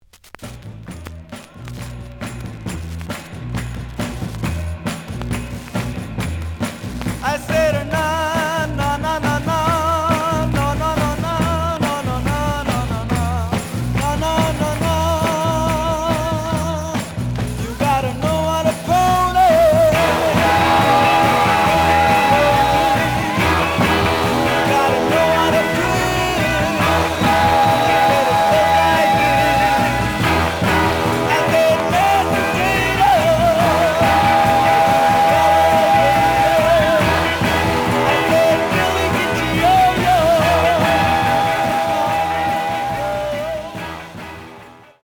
The audio sample is recorded from the actual item.
●Genre: Soul, 60's Soul
Some noise on A side.